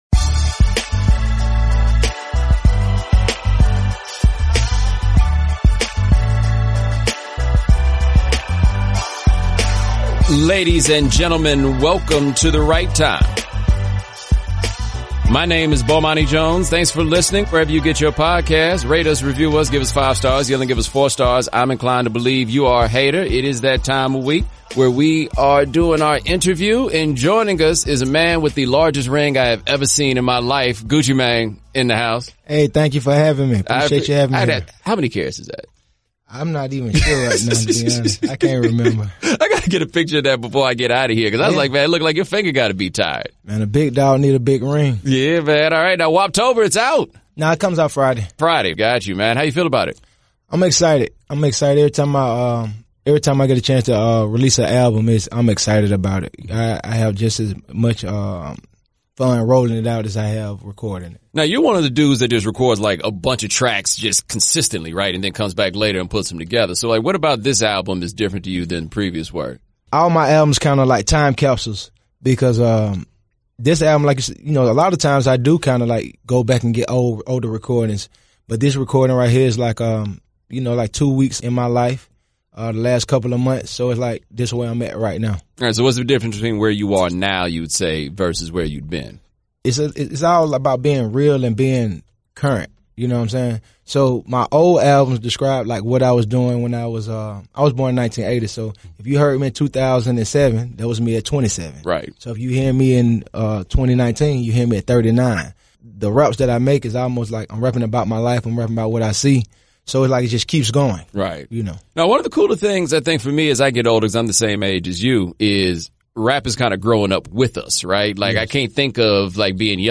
Rapper Gucci Mane joins Bomani to chat about his new album “Woptober II” which drops on October 18th. They dish on what led him to the rap game (4:05), who influenced him early on (14:00), the process behind writing his autobiography (17:38), who he worked with on the new album (20:29), what he’s learned from the record industry and how he can pass it on to other rappers coming up (23:34).